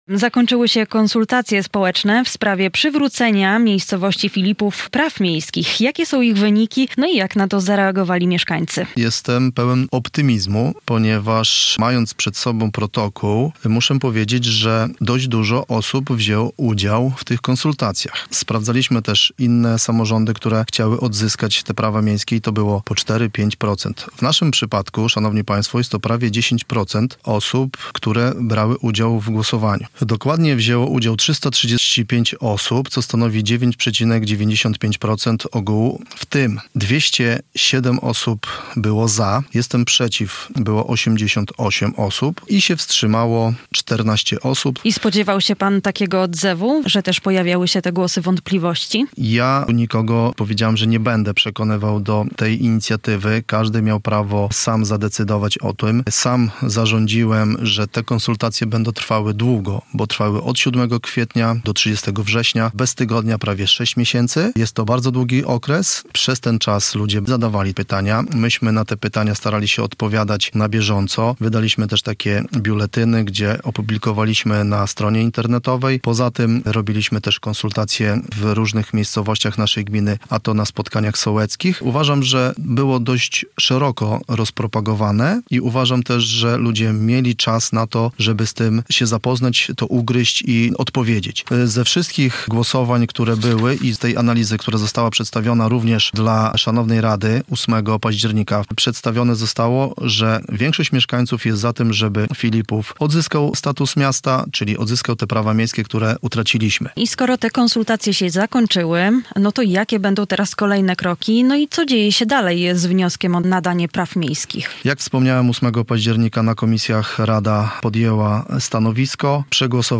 O tym, jak wygląda droga do odzyskania praw miejskich i dlaczego ten krok może odmienić przyszłość miejscowości - rozmawiamy z wójtem Filipowa Tomaszem Rogowskim.